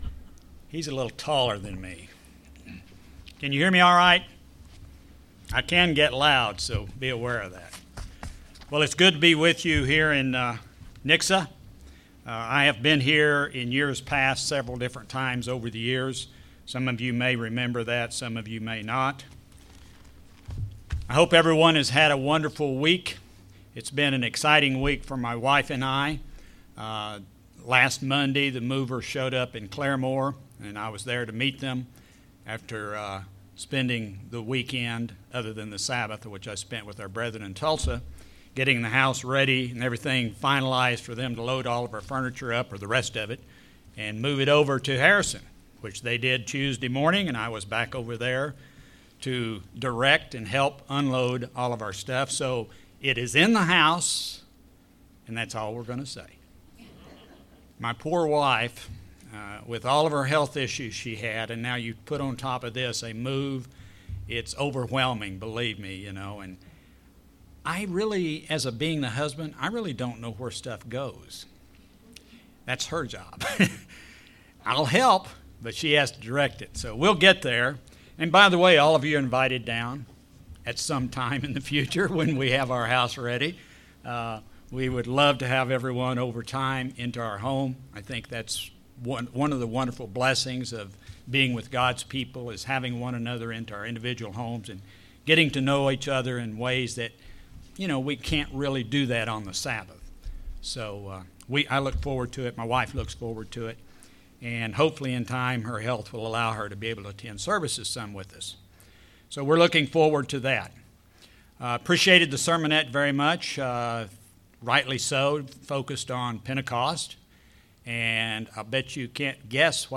A sermon looking at the lessons derived from the time between the Days of Unleavened Bread and Pentecost.